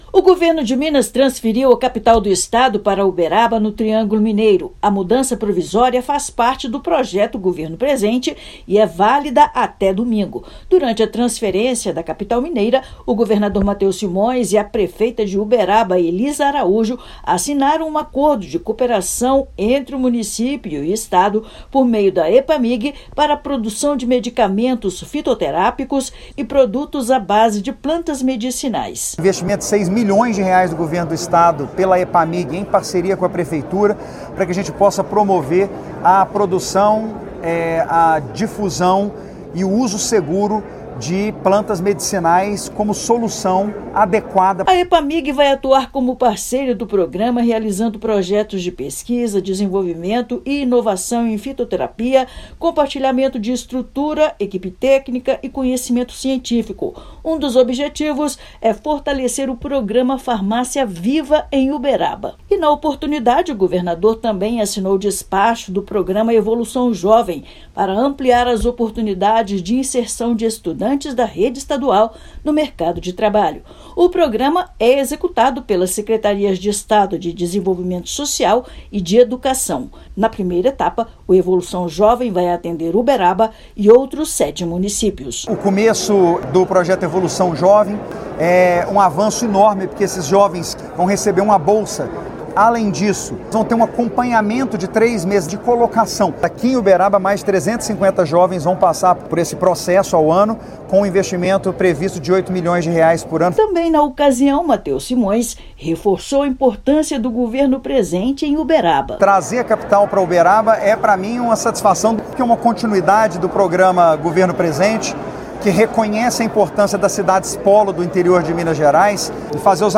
Governador entregou prêmio da Nota Fiscal Mineira e anunciou o programa Evolução Jovem no município. Ouça matéria de rádio.